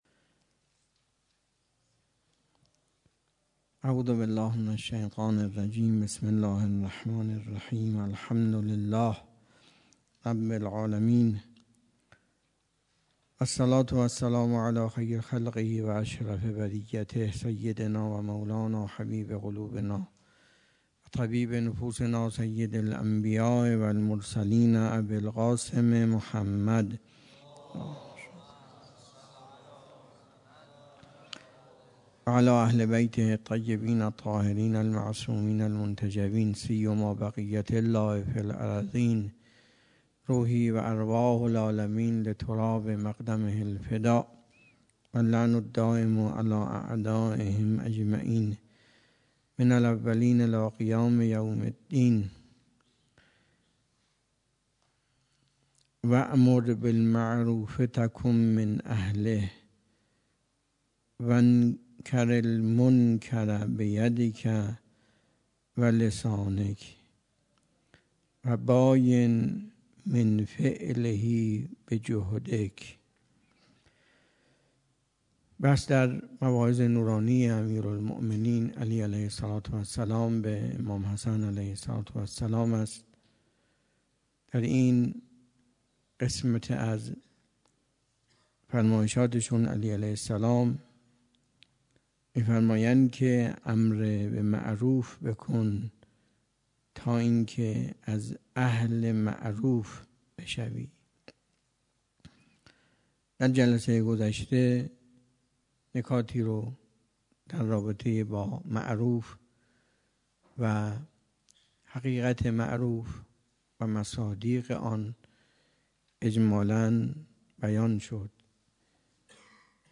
درس اخلاق آیت الله تحریری 97.08.29 سفارشات امیرالمونین (ع) به امام حسن (ع)